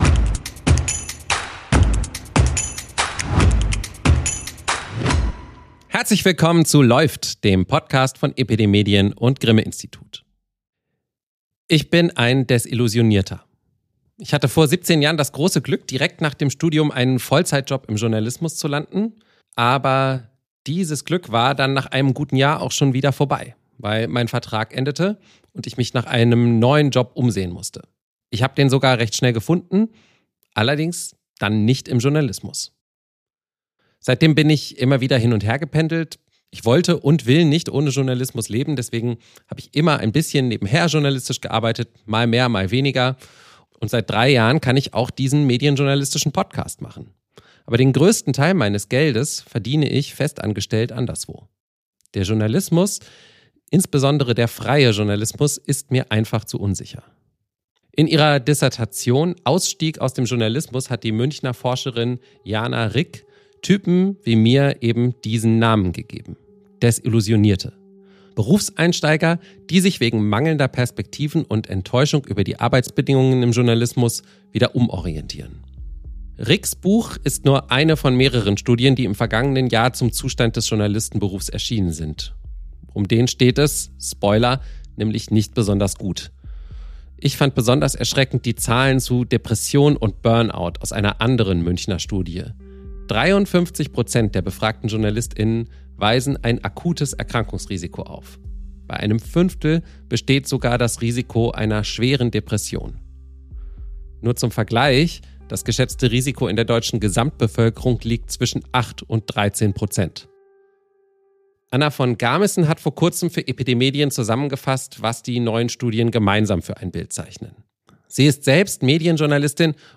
In LÄUFT spricht die Medienwissenschaftlerin und Journalistin